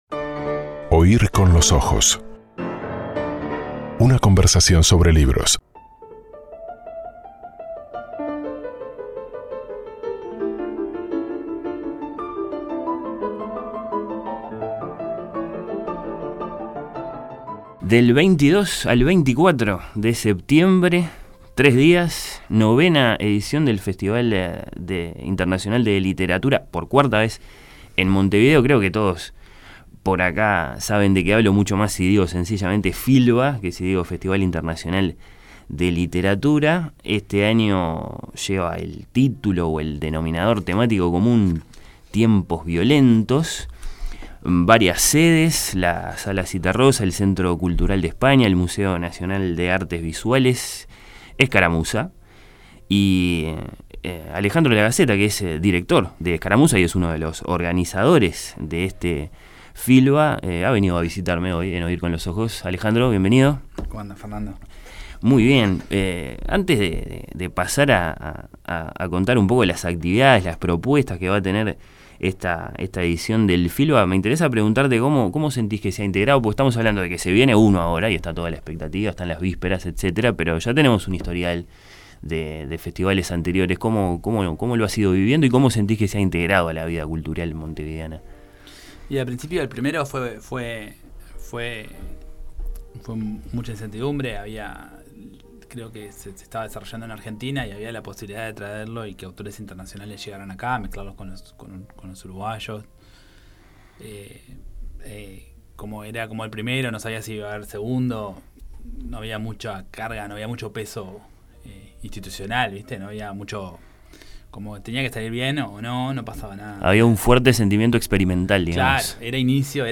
FILBA 2017, edición Montevideo.